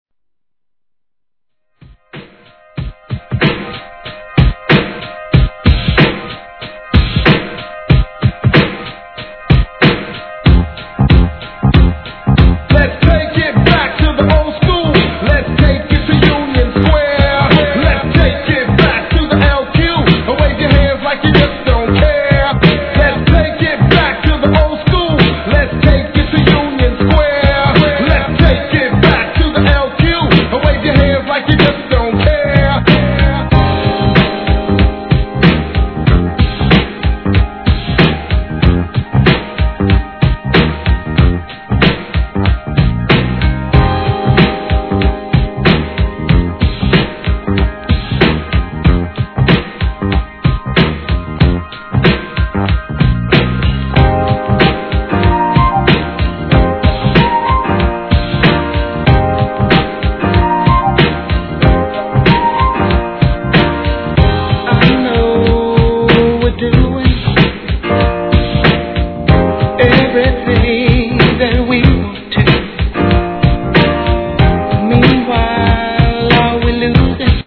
HIP HOP/R&B
年代を問わず、往年の名曲をDJのためにREMIXされたDJ USEコンピ!!